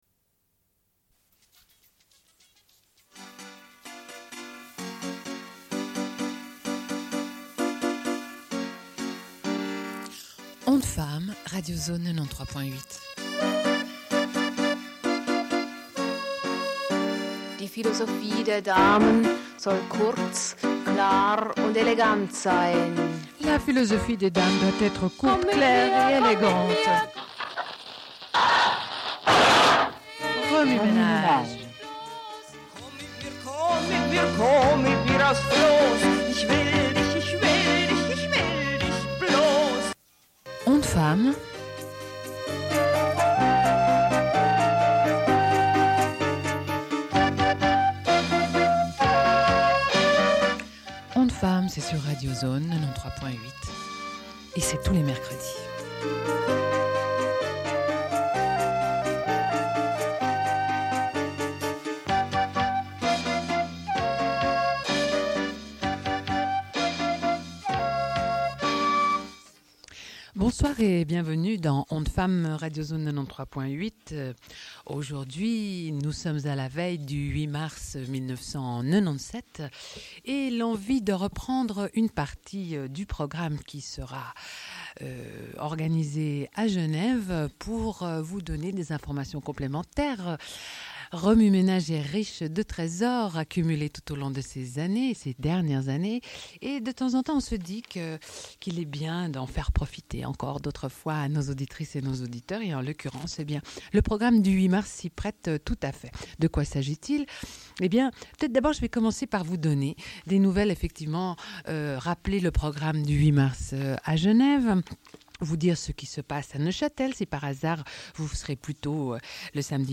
Une cassette audio, face A
Genre access points Radio